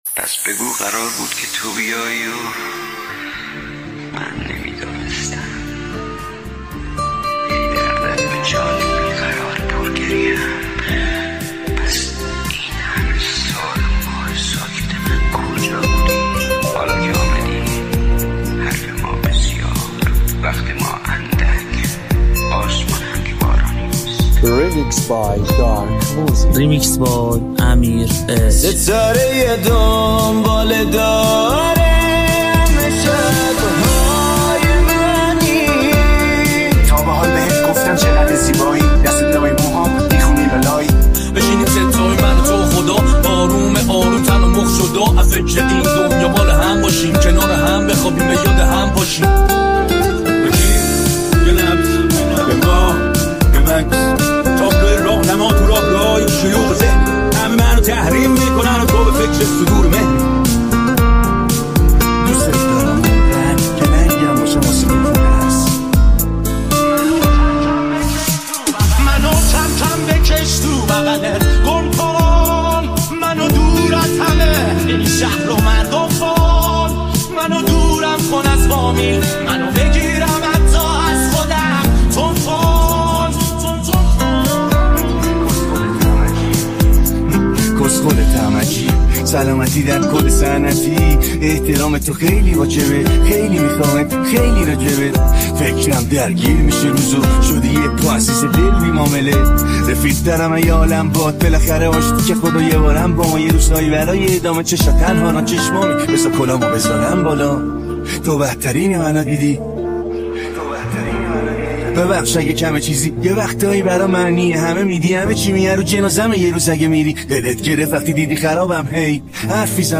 ریمیکس شاد
ریمیکس رپ